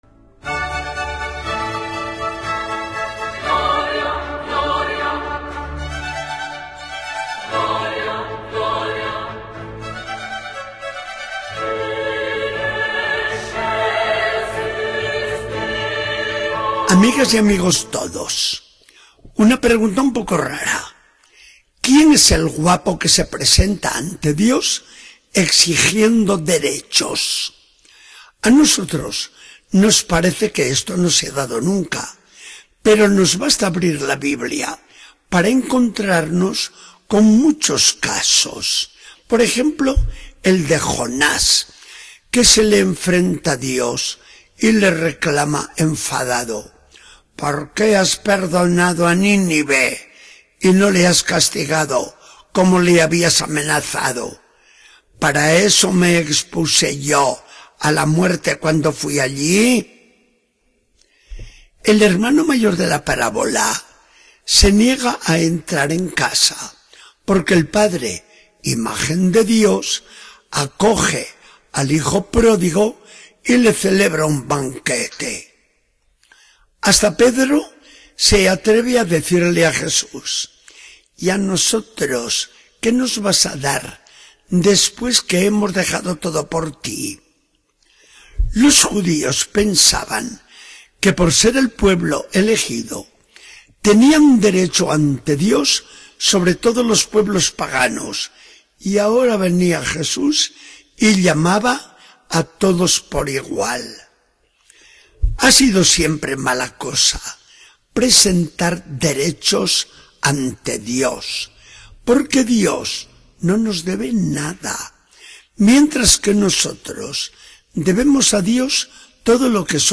Charla del día 21 de septiembre de 2014. Del Evangelio según San Mateo 19, 30.20, 1-16.